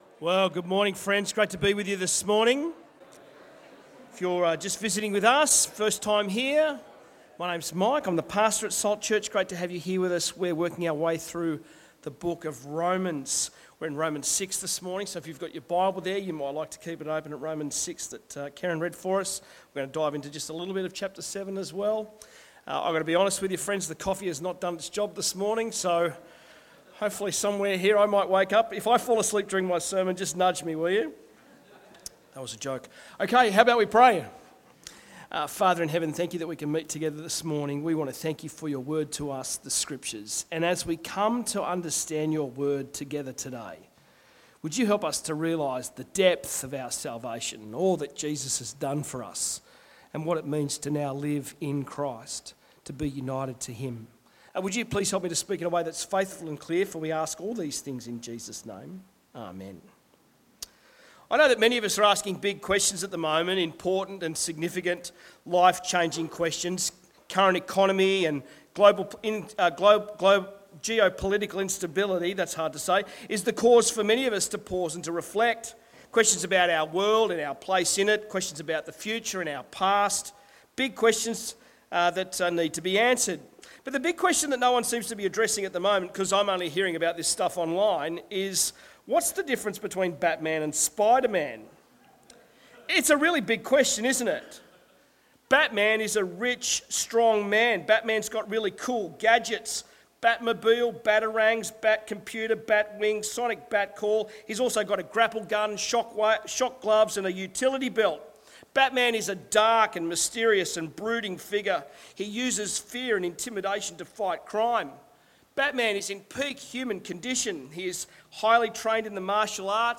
Sermons
Bible talk on Romans 6 from The Life Of Faith series.